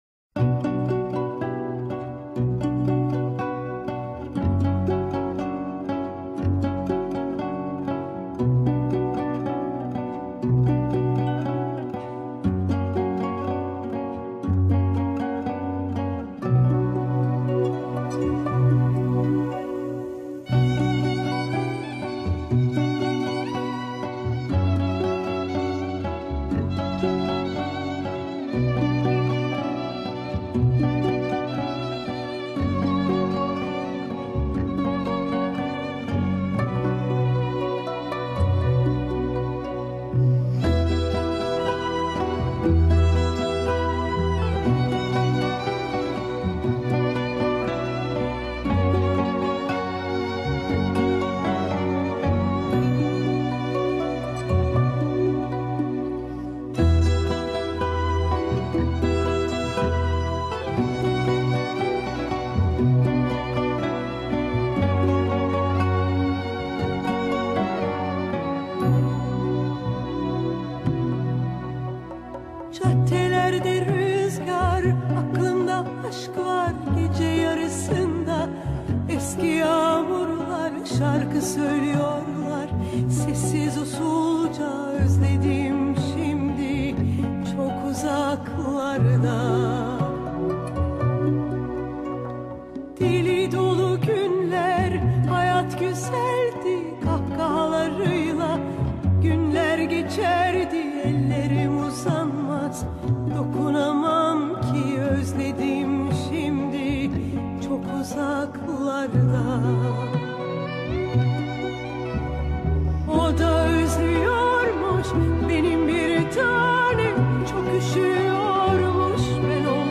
SÖZLÜ